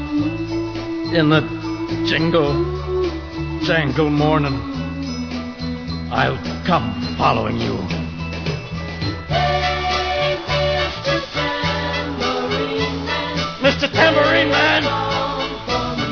Each song is made up of two parts.